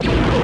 DStar-Gun1